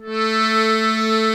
A3 ACCORDI-R.wav